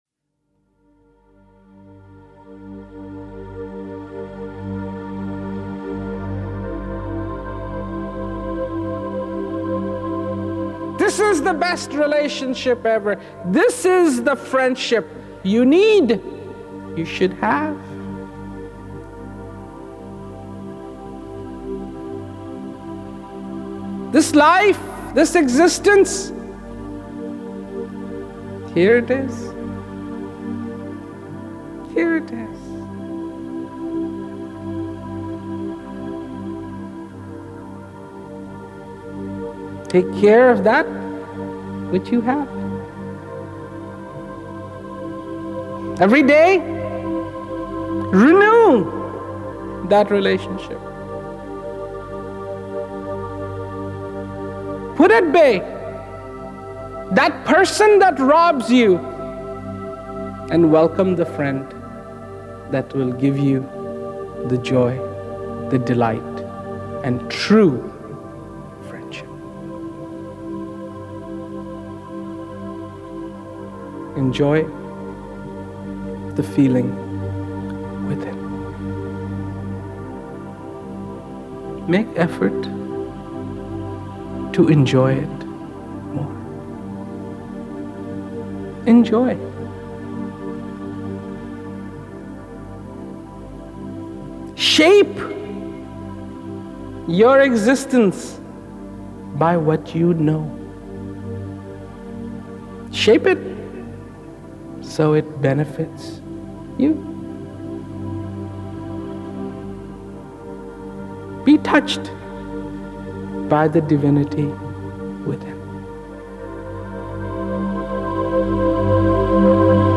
It was a combination of songs recorded by four followers and two sections of Rawat's speeches with background music that attempts to enhance Rawat's impact.
Mp3 copies of these excerpts are recorded at high quality (256Kbps) to ensure no whispered nuance or frenzied climax is missed.